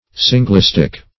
Singlestick \Sin"gle*stick`\, n.